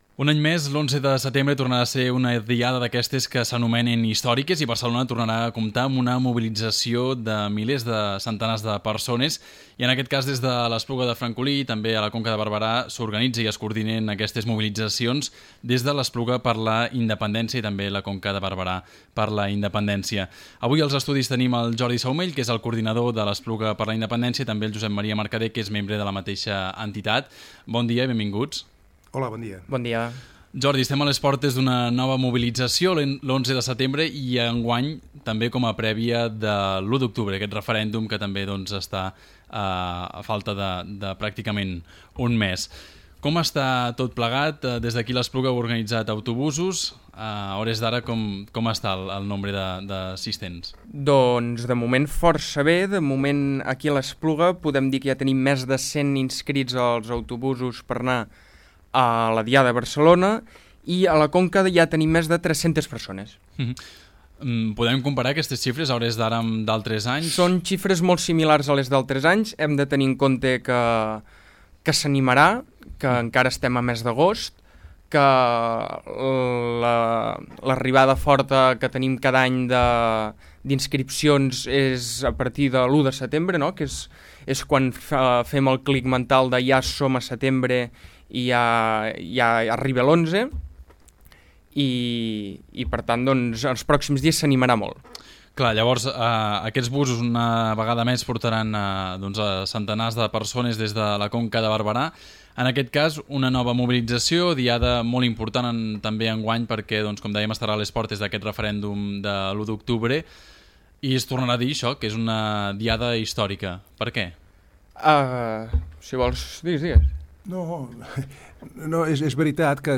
Entrevista-ACN-Espluga-prèvia-11-de-setembre.mp3